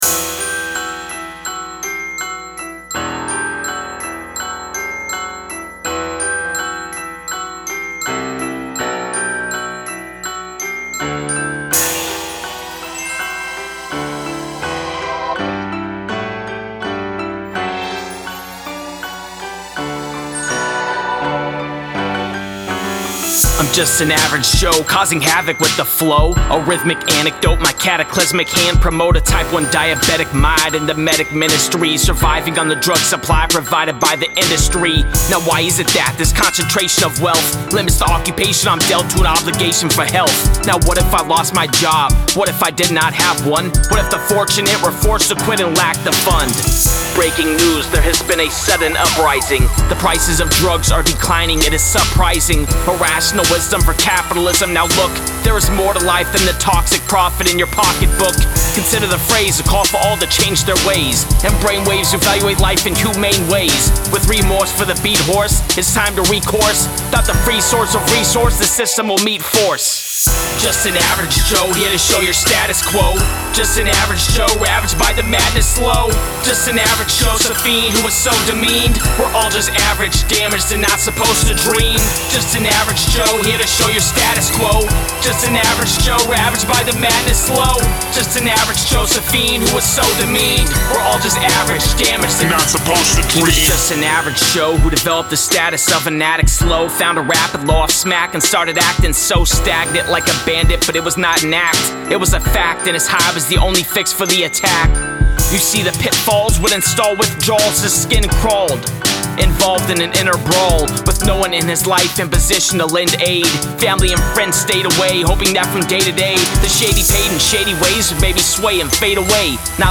Recorded at AD1 Studios